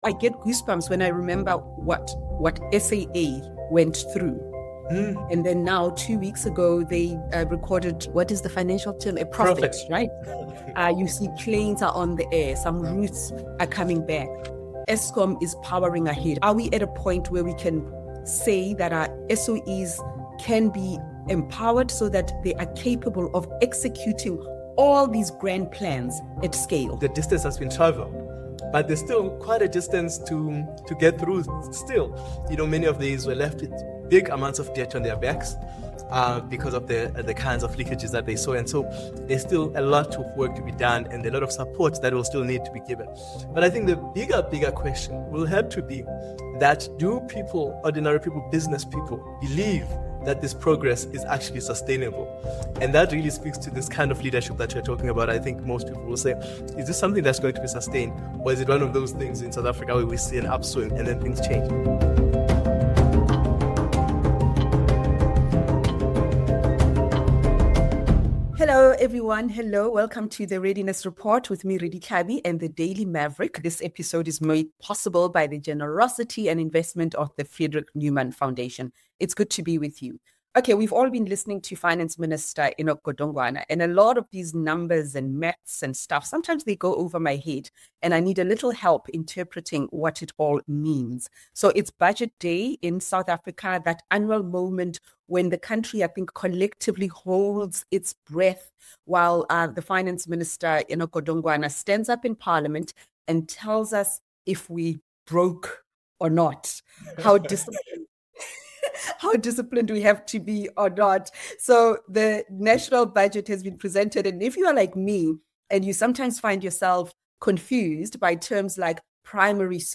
Hosted by: Redi Tlhabi